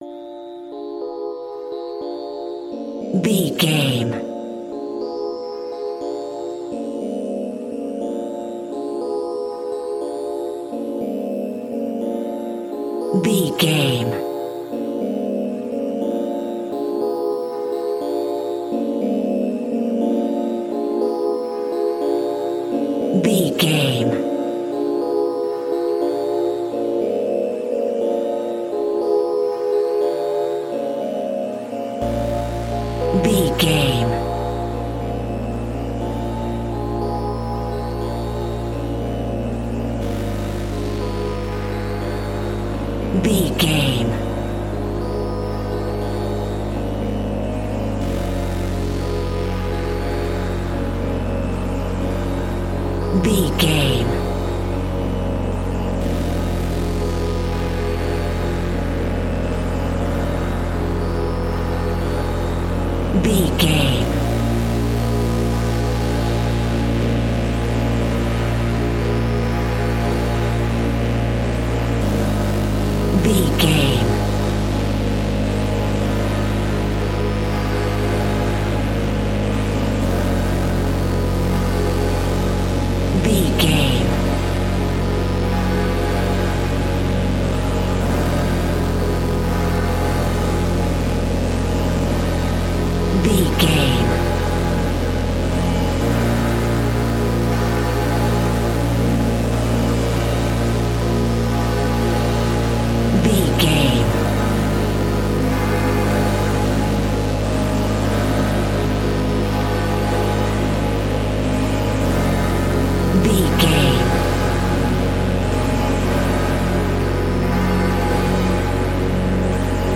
Diminished
ominous
dark
haunting
eerie
synthesiser
creepy
Horror Synths